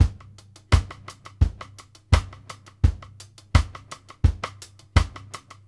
Index of /90_sSampleCDs/Sampleheads - New York City Drumworks VOL-1/Partition F/SP REGGAE 84
4 ON D FLO-R.wav